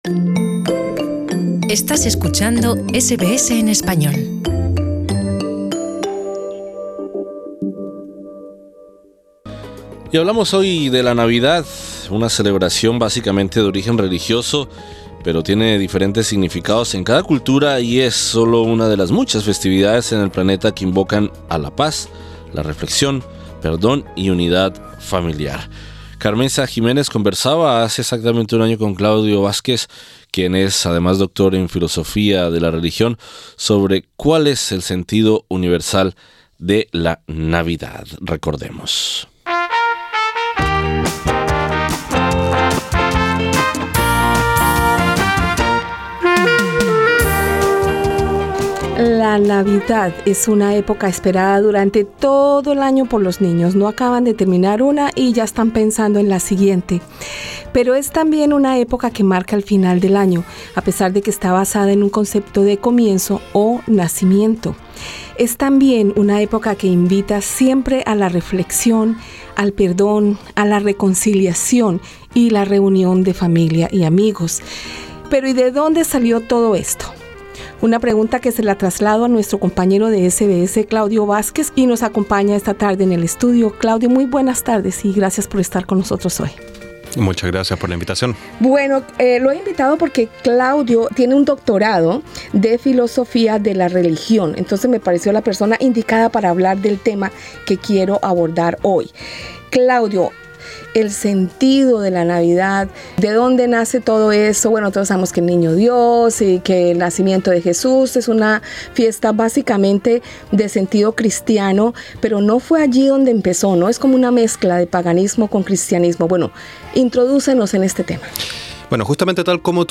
Escucha en nuestro podcast la conversación al respecto.